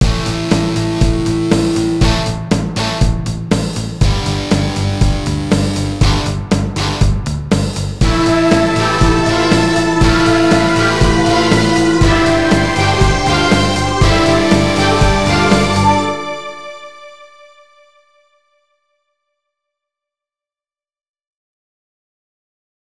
・シンプルなビートでbpsを知る参考曲